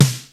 Index of /90_sSampleCDs/300 Drum Machines/Akai XR-10/Snaredrums
Snaredrum-08.wav